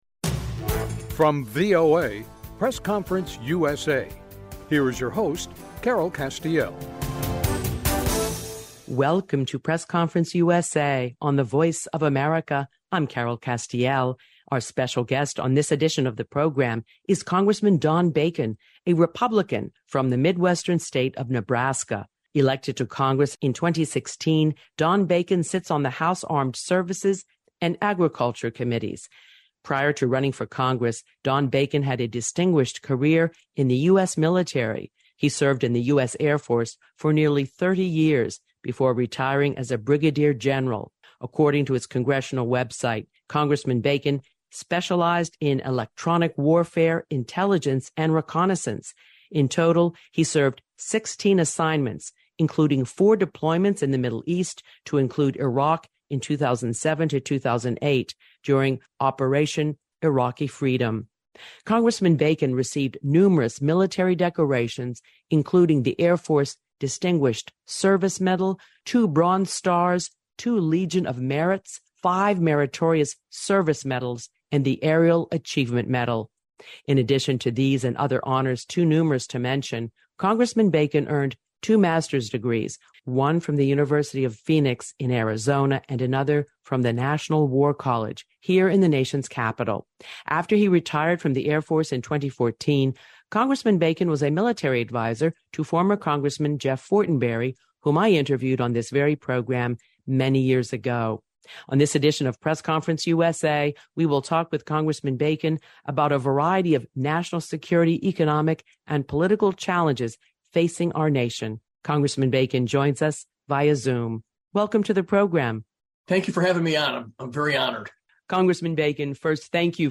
A Conversation with Congressman Don Bacon, R-NE